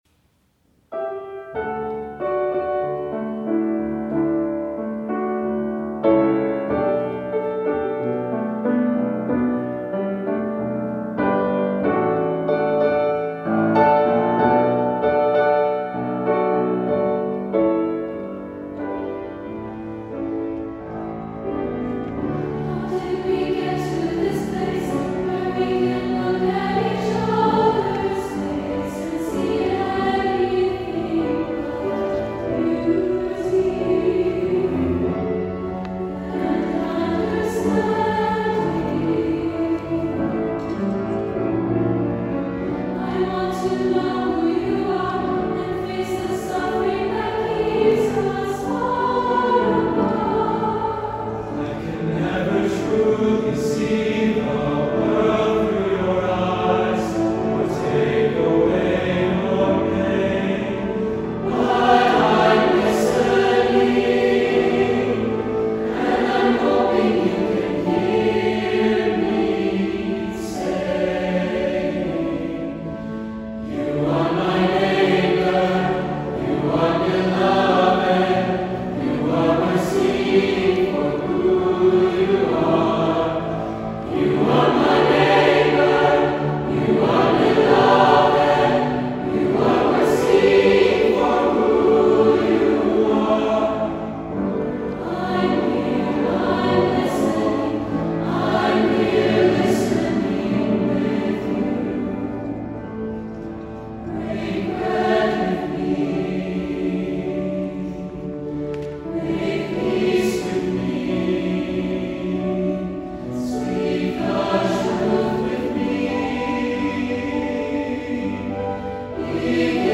1-4 part choir, soloists, piano
(opt. congregational singing)